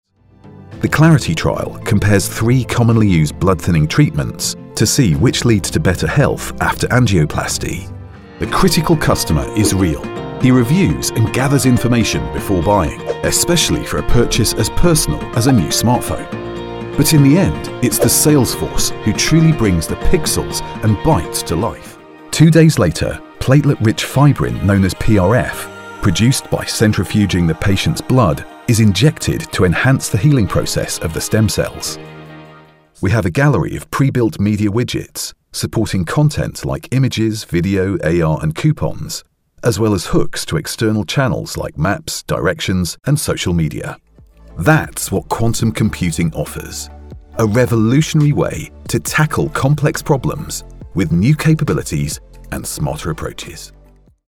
Explainer & Whiteboard Video Voice Overs
Adult (30-50) | Older Sound (50+)